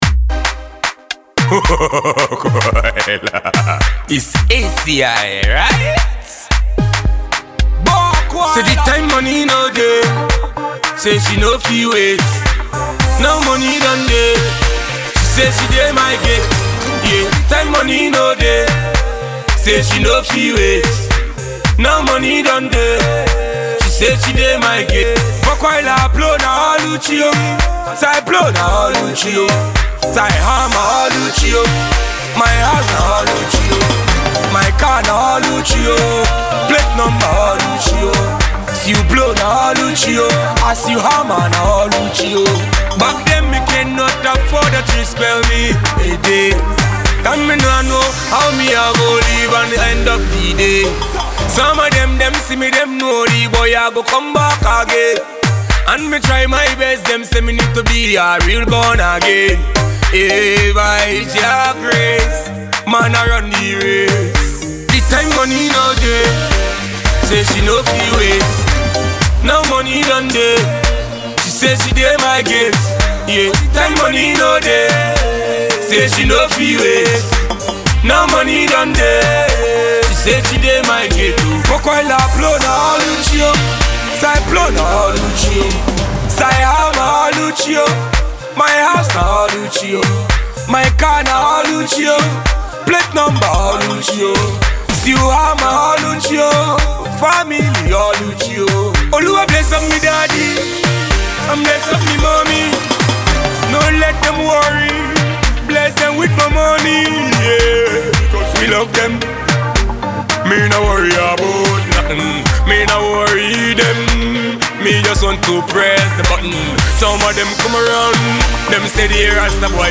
infectious tune
a groovy song with highly instructive message